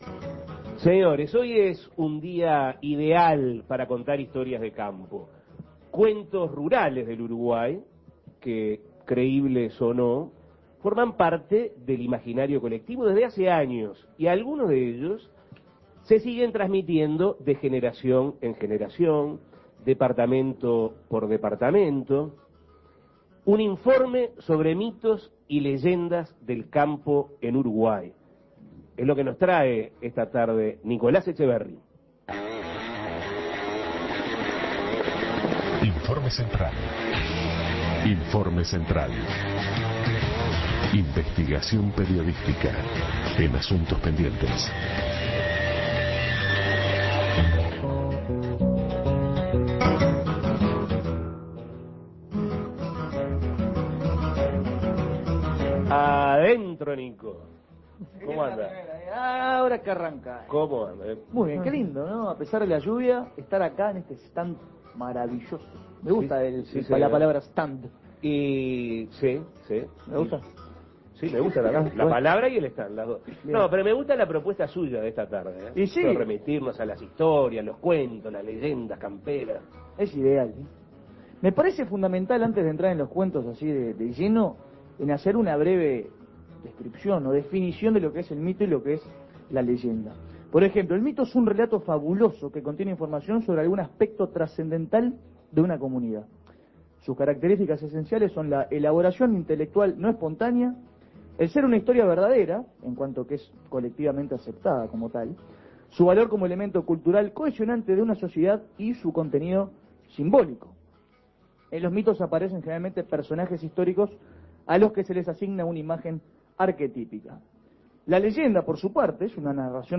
comparte el fenómeno de mitos y leyendas del campo uruguayo desde la Expo Prado 2007. Apariciones, criaturas, intervenciones divinas y terroríficas, con un vacabulario tan particular como el del gaucho.
Informes